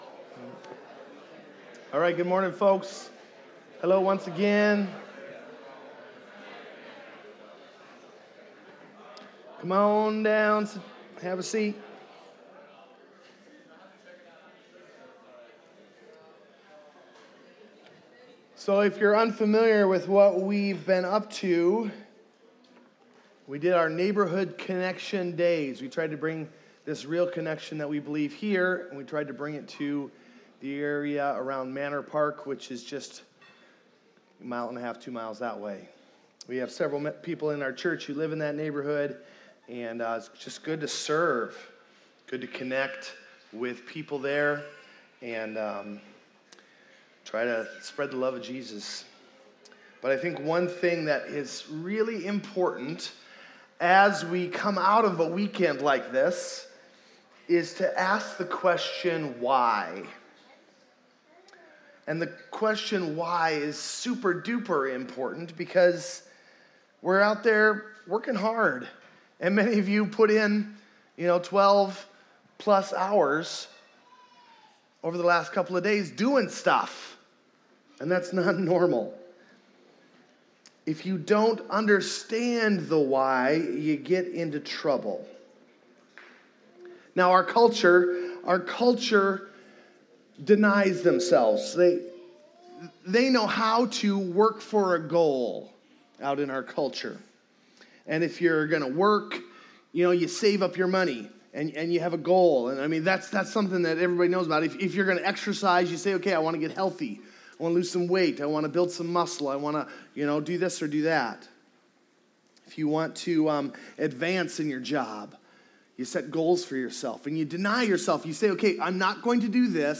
Video Audio Download Audio Home Resources Sermons Follow Jesus and Find Your Life Aug 10 Follow Jesus and Find Your Life Let's recognize the upside down teaching of Jesus and figure out what it means to swim against the cultural flow.